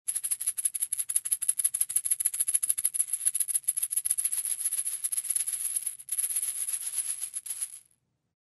Звуки маракасов
Дрожащий звук маракасов